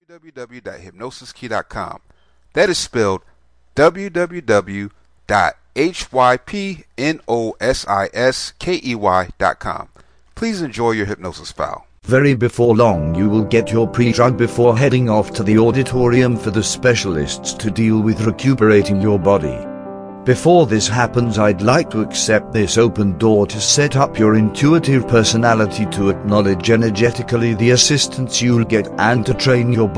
Presurgery Self Hypnosis Mp3